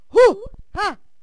valkyrie_attack4.wav